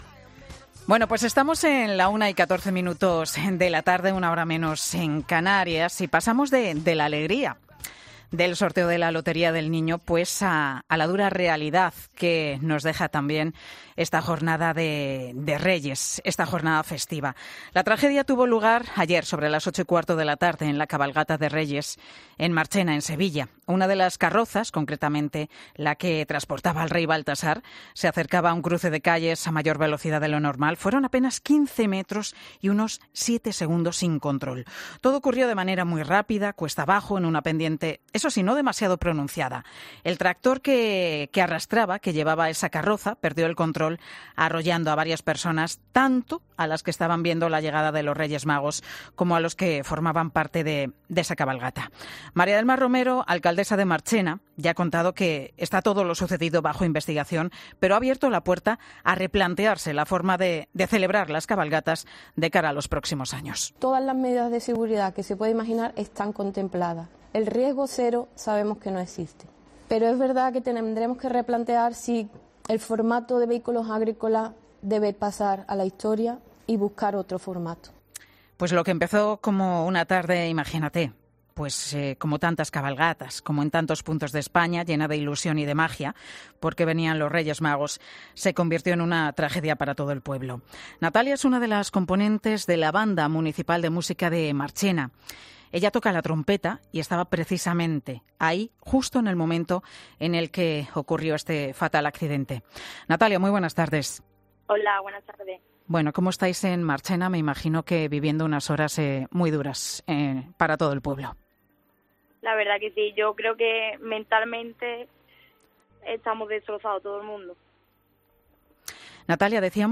Testigo de la tragedia de la cabalgata de Reyes de Marchena: "Estamos destrozados"